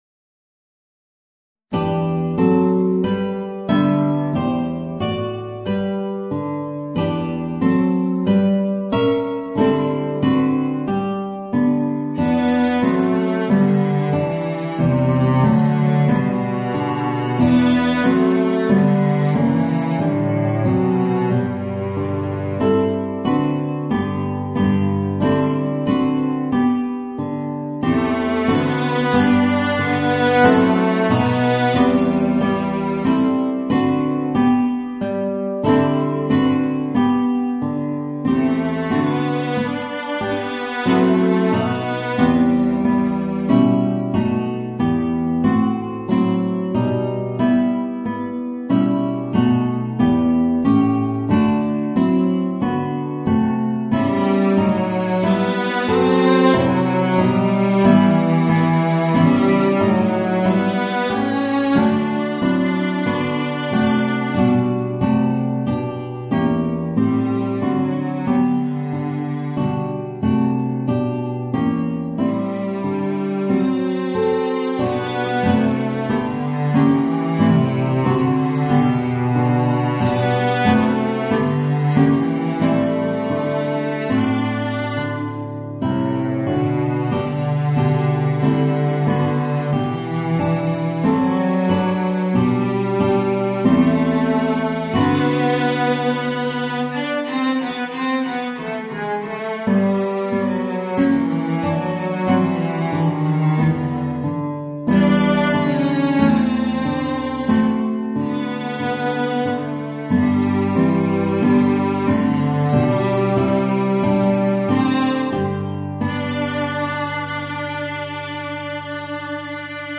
Voicing: Guitar and Violoncello